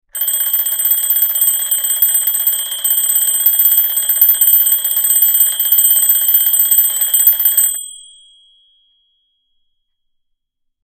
Catégorie: Alarmes